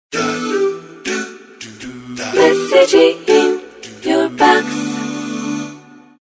Message tone 05.mp3